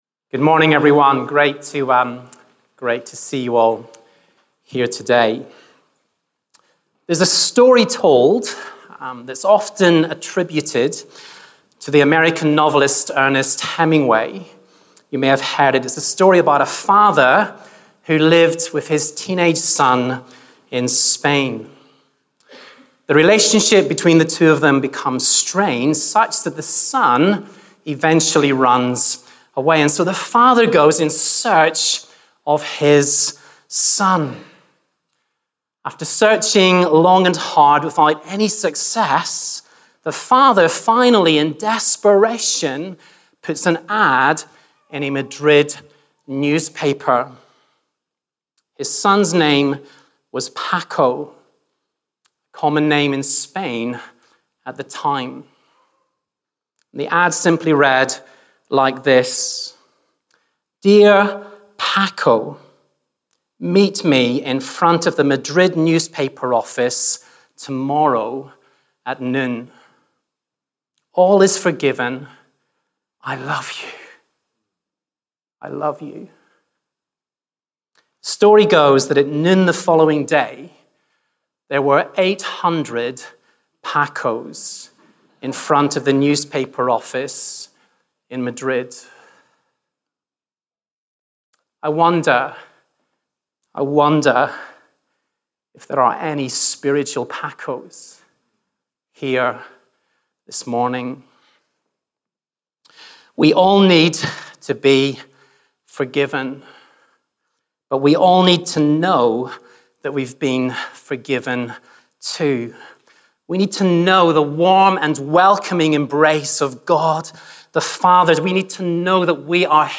Teaching from the Sunday morning meetings of King's Church Edinburgh.